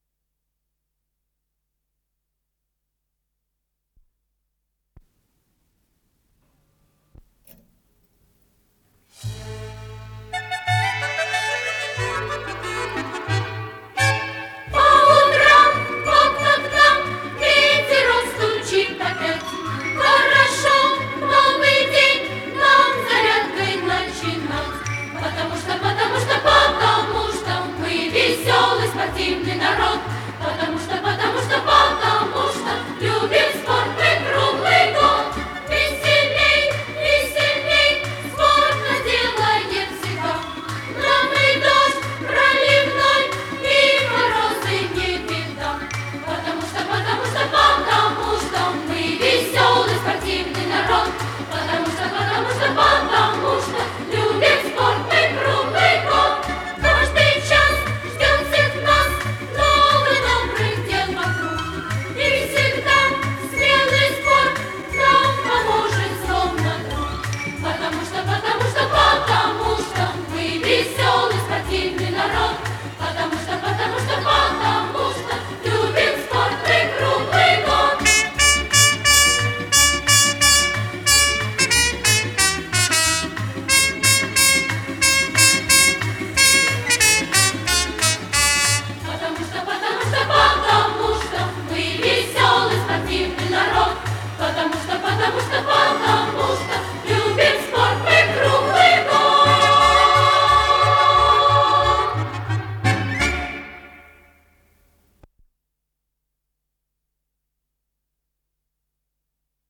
с профессиональной магнитной ленты
ВариантМоно